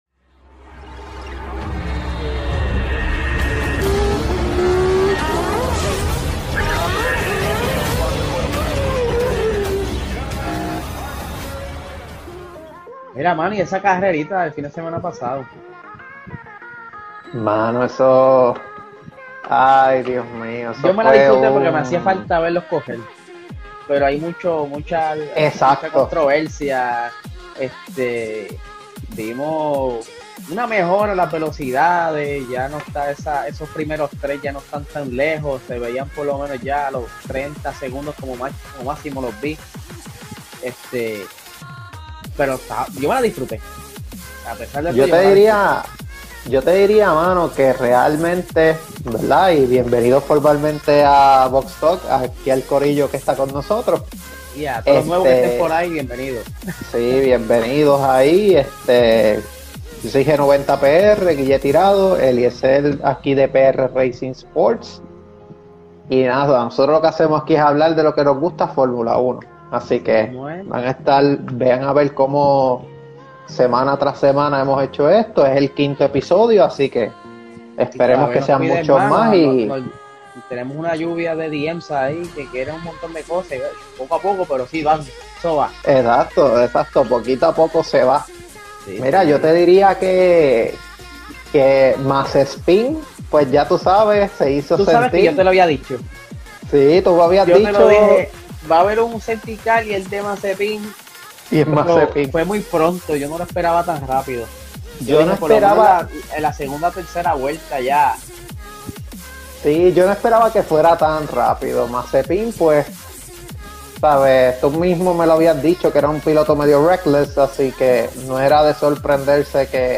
Estos son los primeros 30 minutos del programa Box Talk del pasado viernes 2 de abril. El mismo fue live